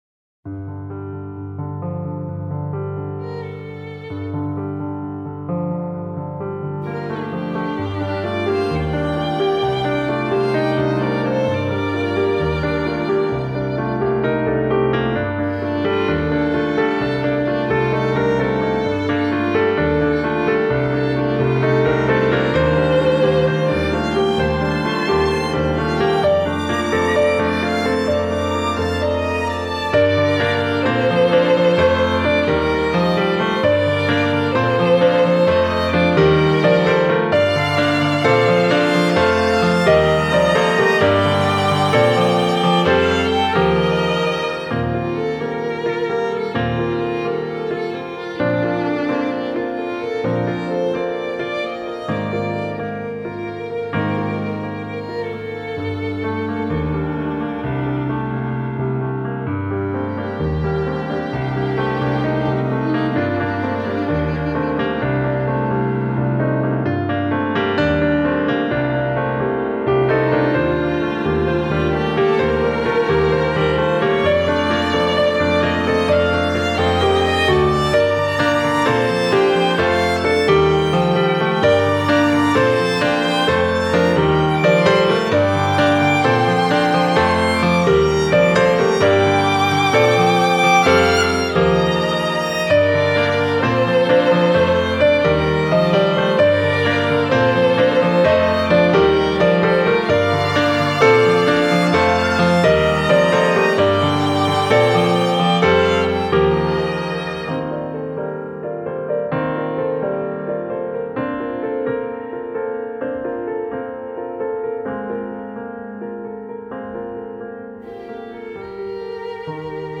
Animé(пиано, скрипка и альт